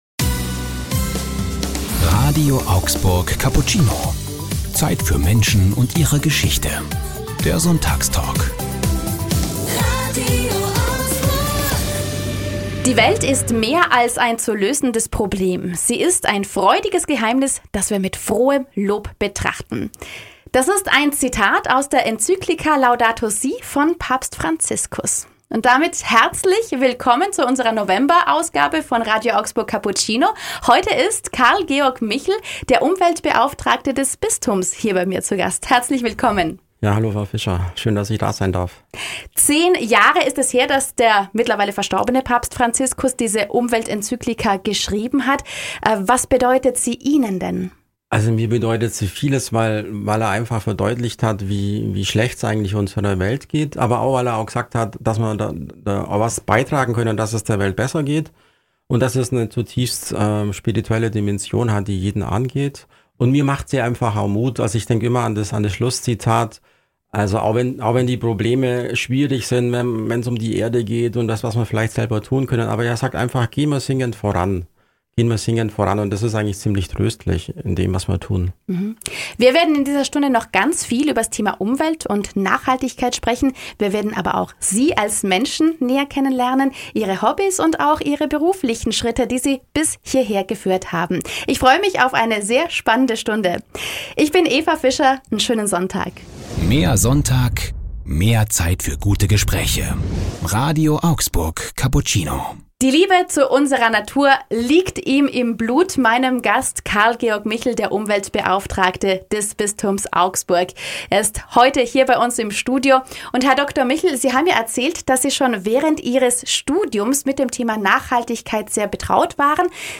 Sonntagstalk ~ RADIO AUGSBURG Cappuccino Podcast